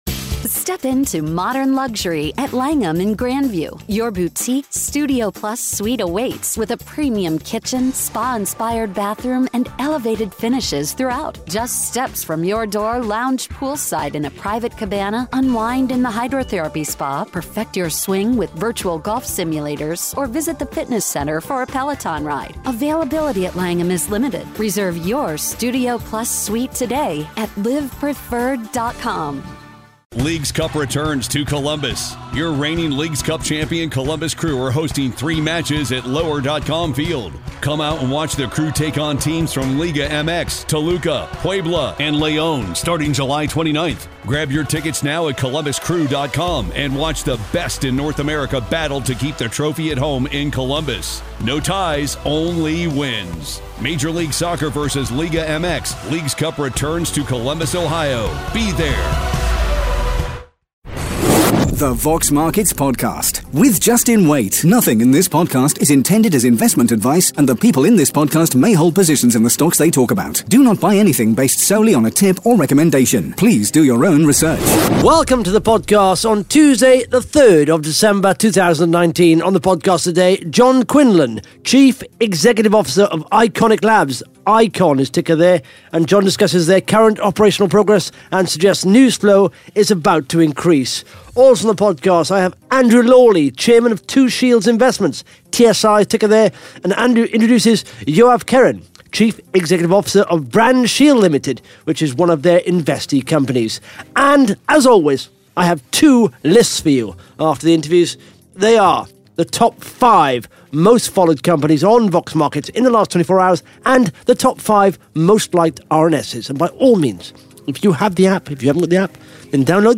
(Interview starts at 15 minutes 25 seconds) Plus the Top 5 Most Followed Companies & the Top 5 Most Liked RNS’s on Vox Markets in the last 24 hours.